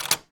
door_lock_close_02.wav